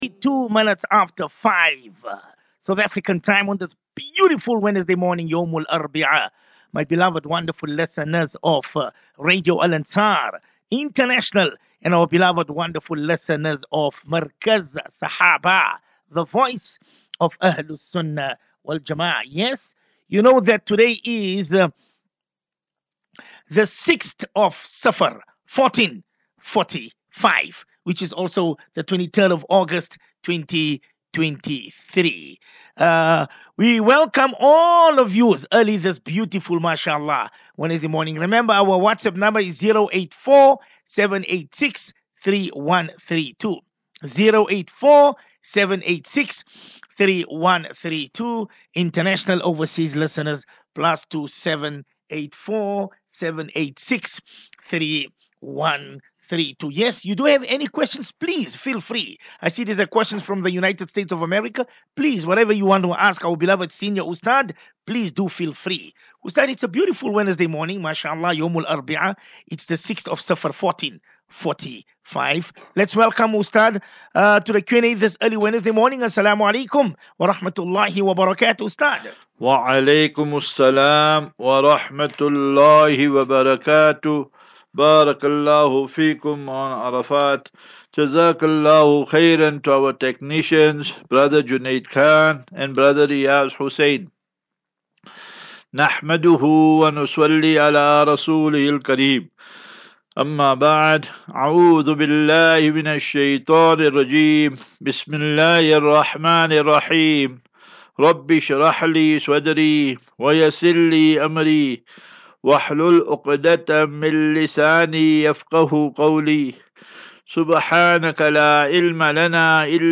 As Safinatu Ilal Jannah Naseeha and Q and A 23 Aug 23 August 2023.